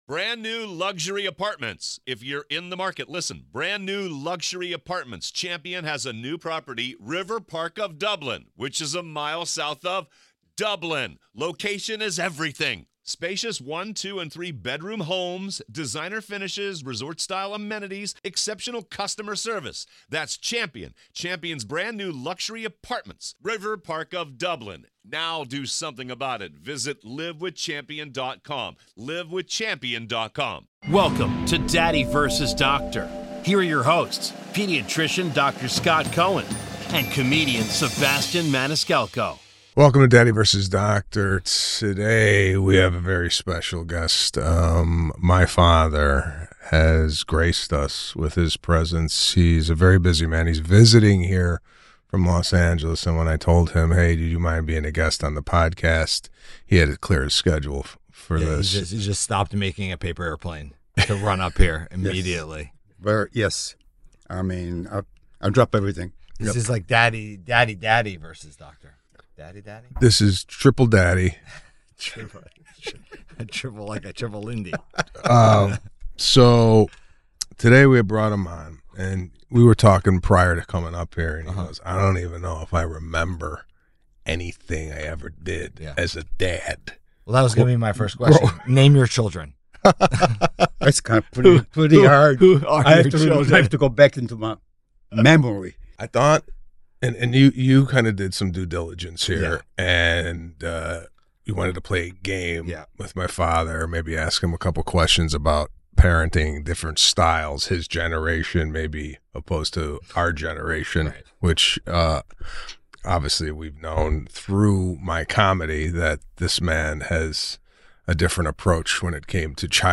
The trio share a laugh over Old World parenting techniques that include potato skins, cure-all ointments, and magical appendages.